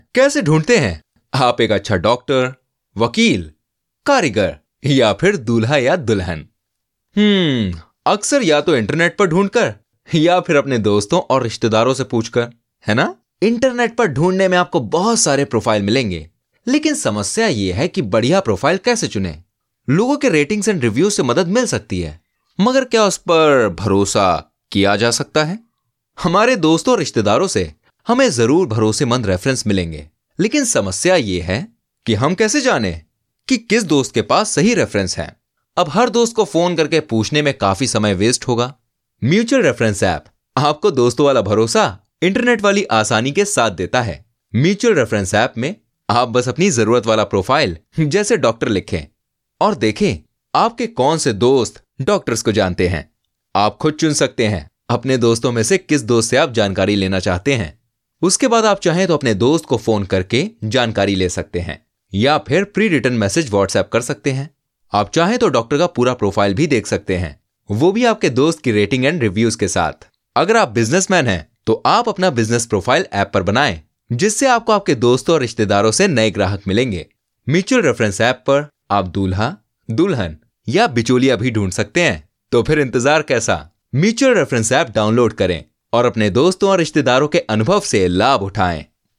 0707Hindi_voice__hindi_voice_artist.mp3